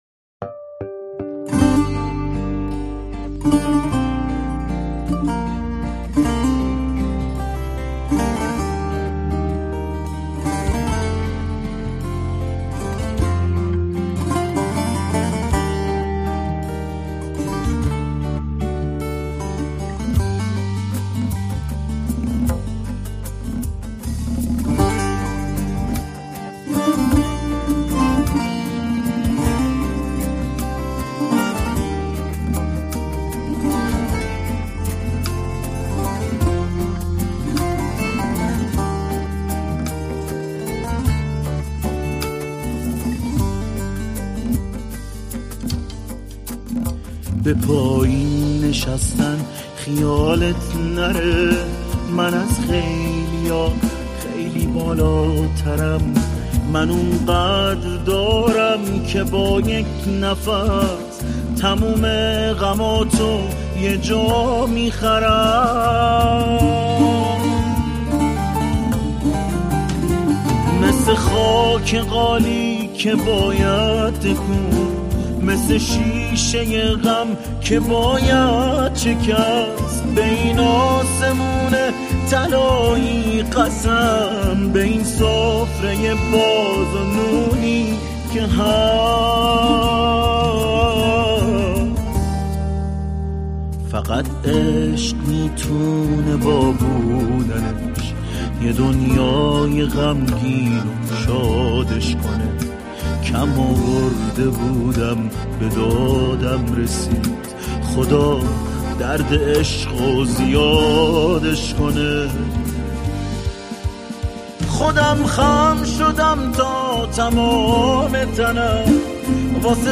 دسته : الکترونیک